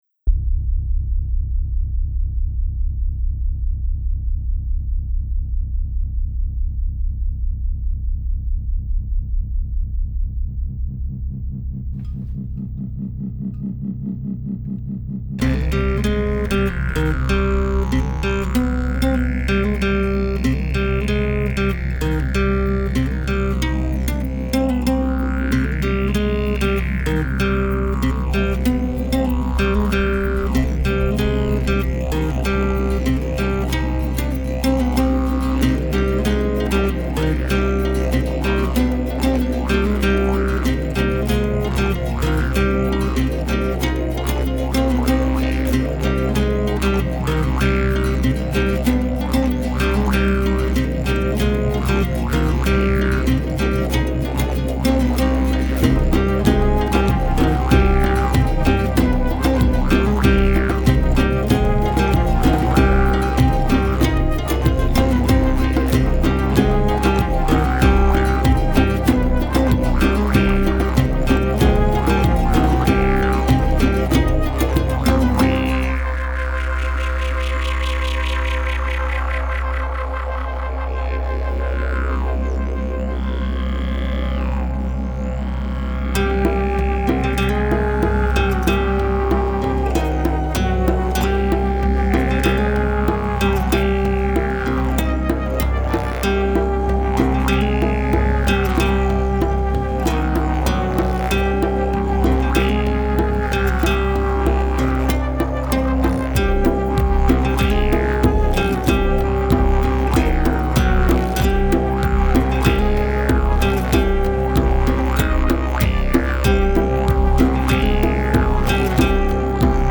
Le didge, fidèle à mes habitudes n'est là qu'en accompagnement il le vaut bien!
C'est marrant, le coté mexicain avec le coté orientale sur certain passage avec des interludes un peu glauquasses.
Un bon gros mélange de sonorités différentes, on secoue le tout, et hop...avec une rythmique bien entrainante, ça fait plaisir à écouter.
Y a un côté transe mexicaine avec la guitare, cool
Revenir à « Compositions personnelles didgeridoo »